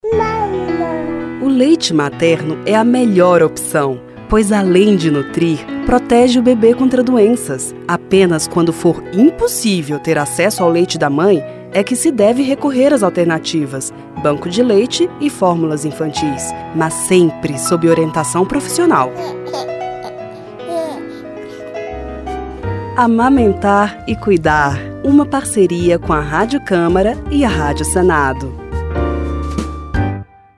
São cinco spots de 30 segundos cada.